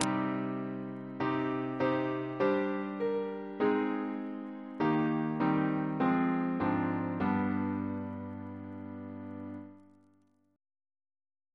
CCP: Chant sampler
Single chant in C minor Composer: Henry G. Ley (1887-1962) Reference psalters: H1982: S257; RSCM: 191